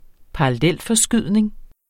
Udtale [ -fʌˌsgyðˀneŋ ]